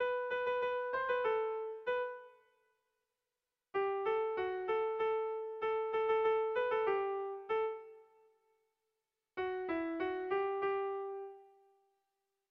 Haurrentzakoa
Lauko txikia (hg) / Bi puntuko txikia (ip)
AB